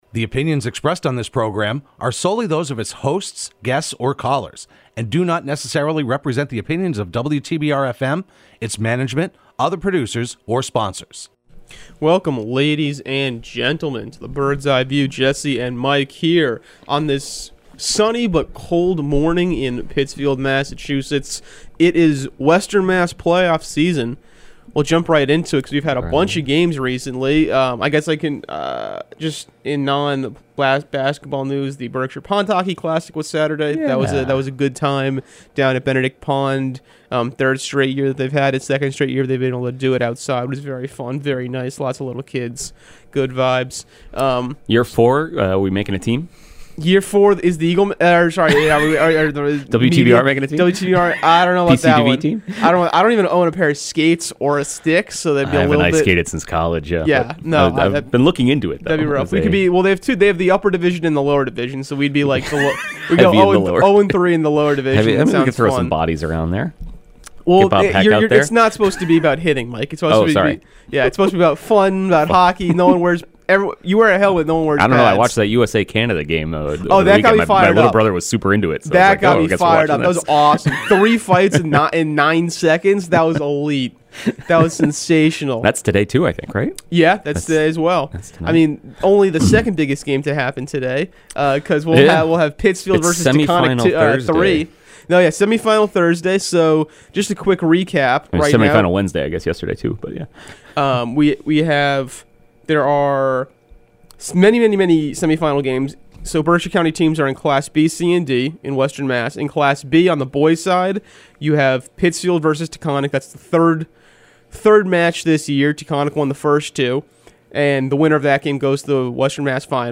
Broadcast live every Thursday morning at 10am on WTBR.